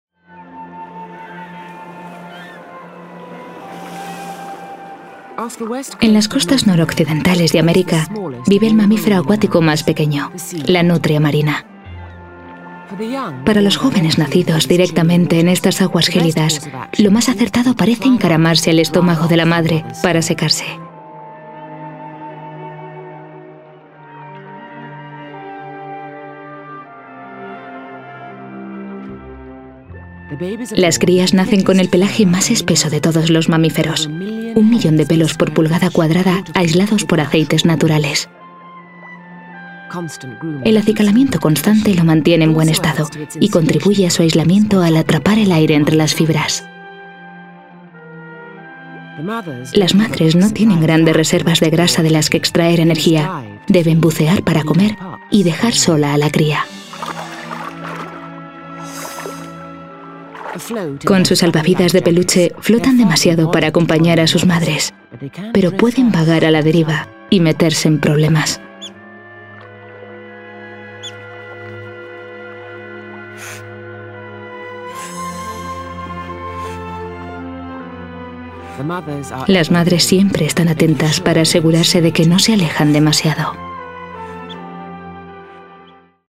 Voz media cálida con registros de tonos altos y timbrada para locuciones y narraciones.
Soprano.
kastilisch
Sprechprobe: Industrie (Muttersprache):